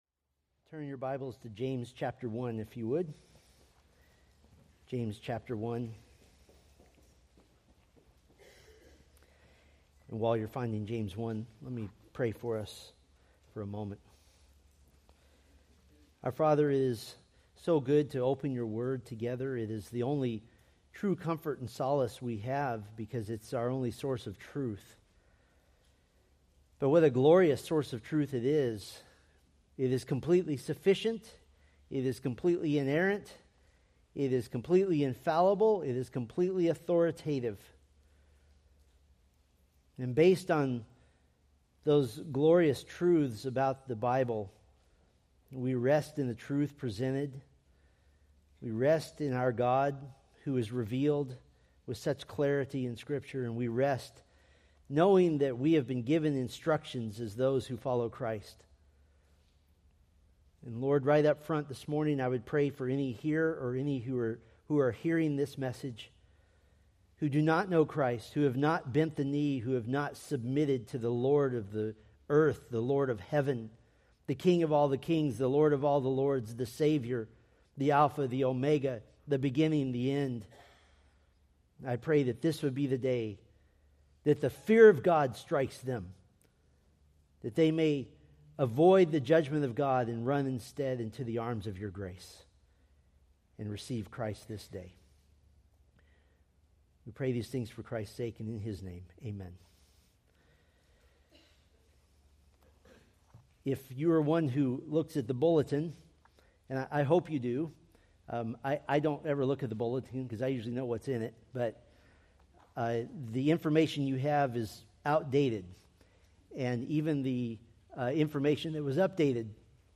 Preached September 22, 2024 from James 1:13-15